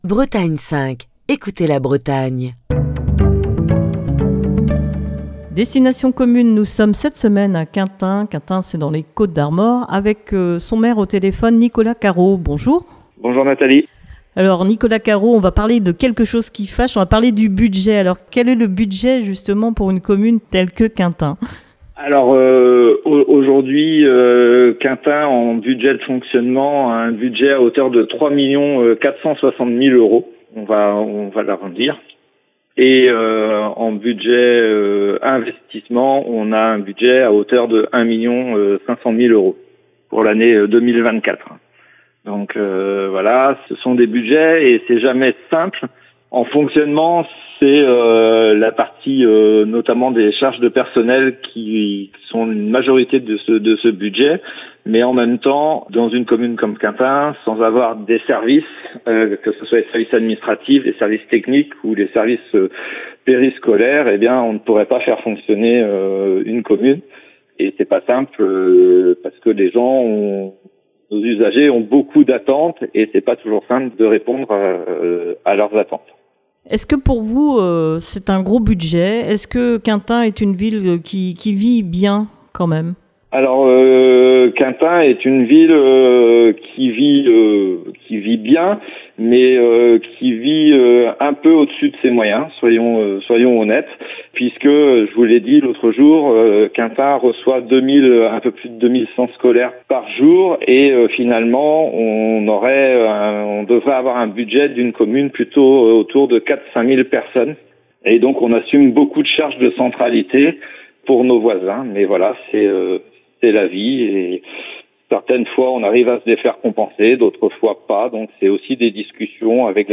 est au téléphone avec le maire de Quintin